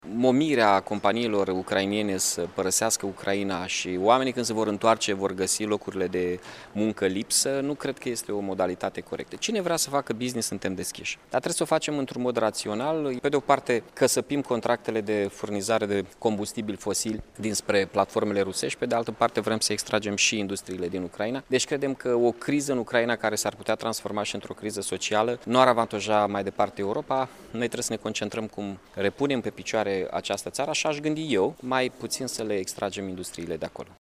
Primarul Iaşului Mihai Chirica a precizat, astăzi, în deschiderea unui forum transfrontalier că în această perioadă nu trebuie gândită o transferare a firmelor din Ucraina în România deoarece acest lucru ar determina o agravare a crizei din Ucraina.